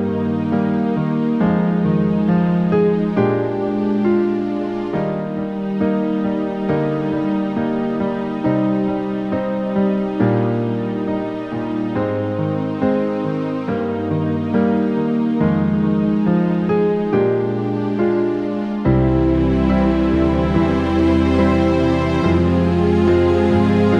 Album Version Easy Listening 3:04 Buy £1.50